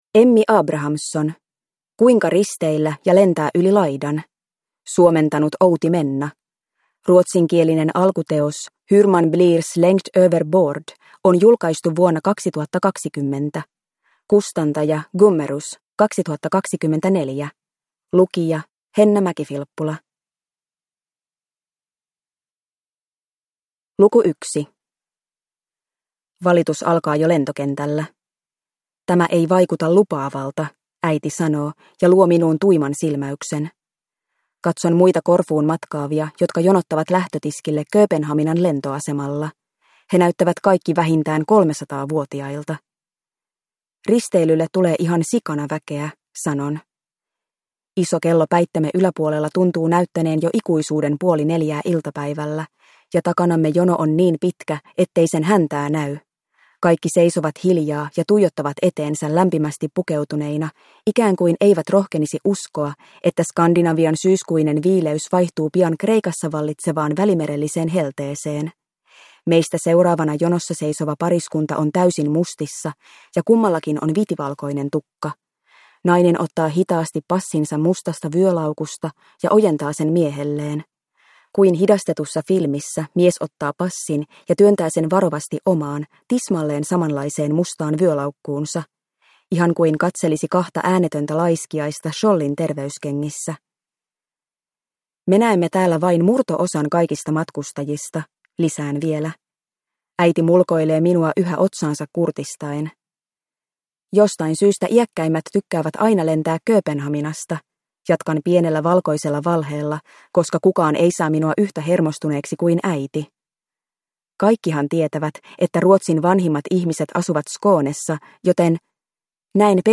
Kuinka risteillä ja lentää yli laidan – Ljudbok